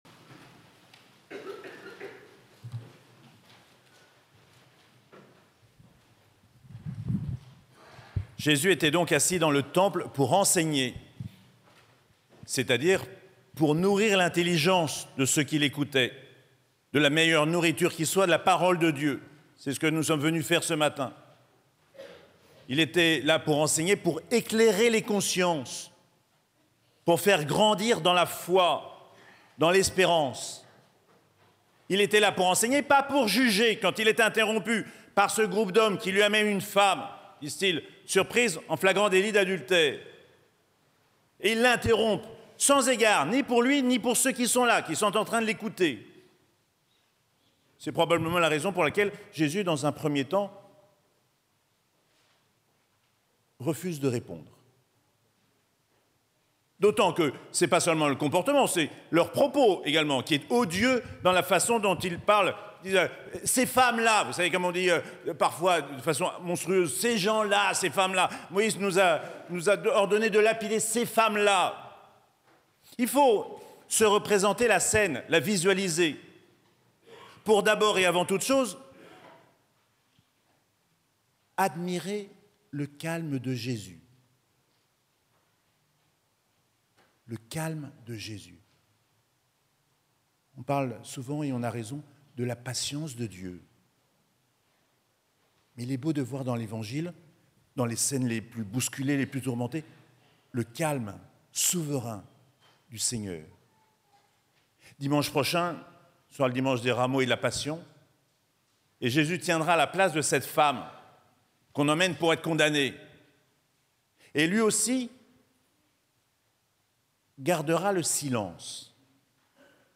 5ème dimanche du Carême - 6 avril 2025
Précédentes homélies